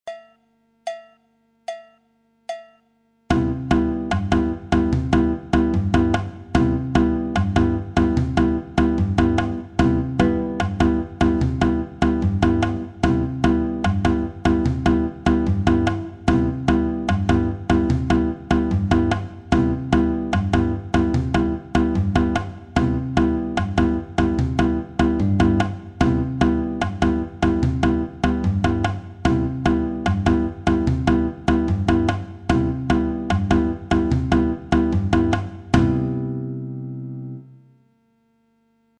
La bossa nova figure n° 3
Une variante bossa nova 3 alternance des basses ( imitation surdo), avec tambourim bossa 2 et surdo.